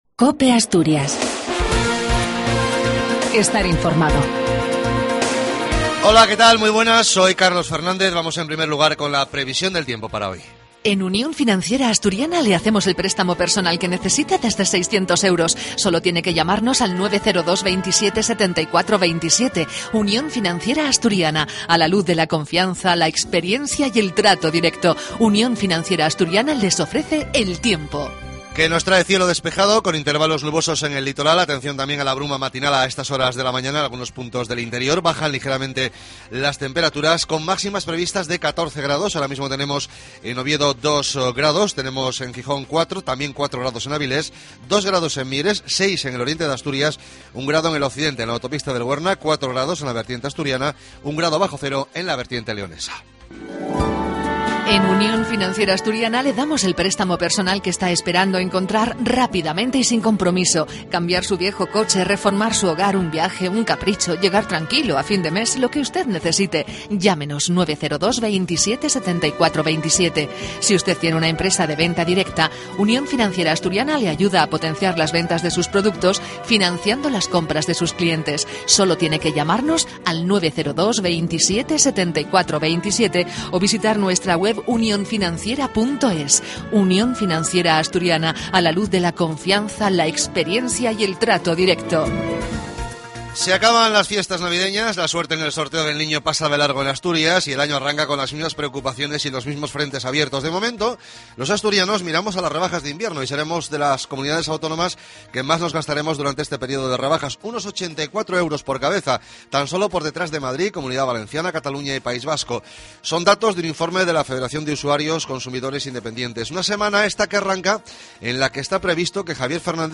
AUDIO: LAS NOTICIAS DE ASTURIAS A PRIMERA HORA DE LA MAÑANA.